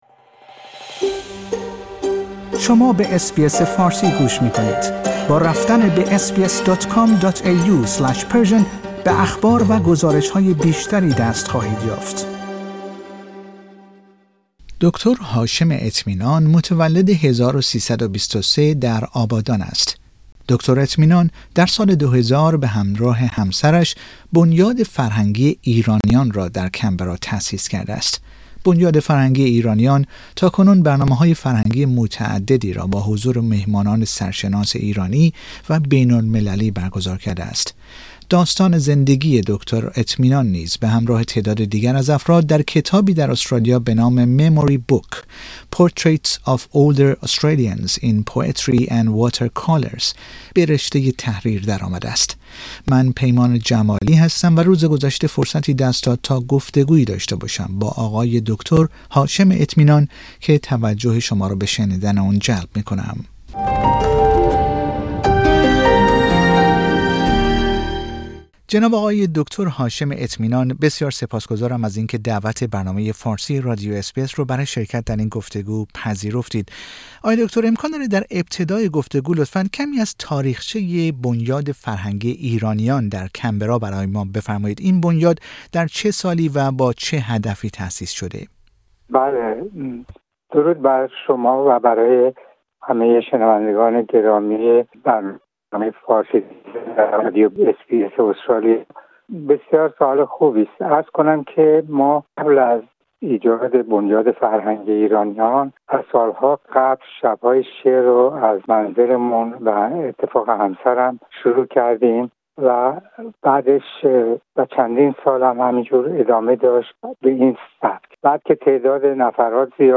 گفتگو با رادیو اس بی اس فارسی